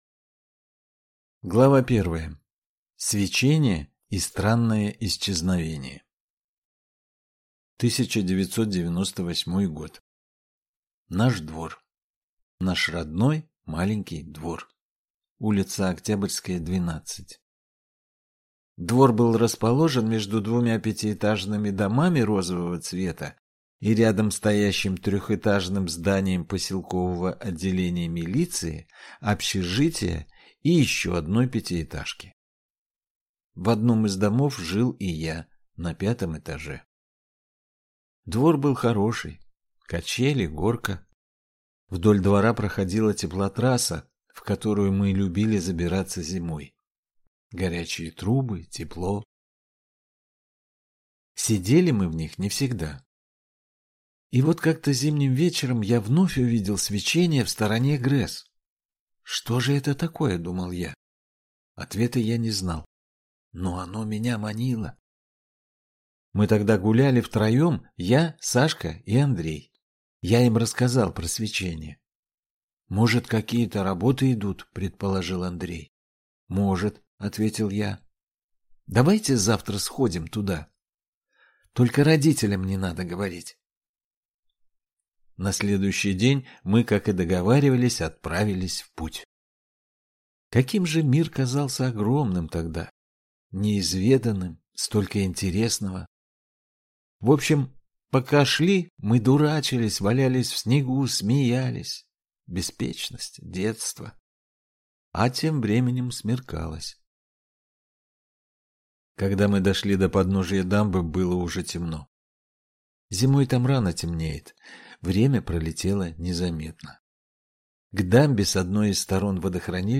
Аудиокнига Метель | Библиотека аудиокниг